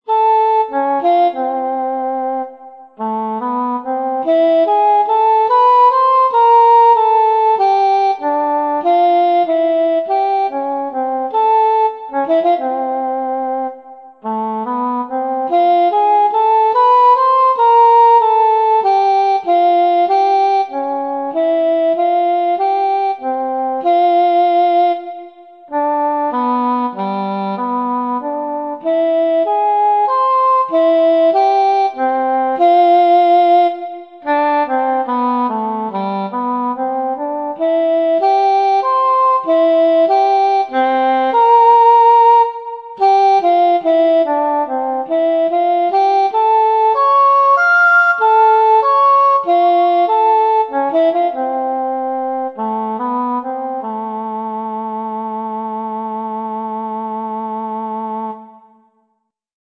Rêverie, for English horn, in F major
This piece is meant to help the audience muse, dream, or simply relax for a short while. It somehow sounds like an impromptu.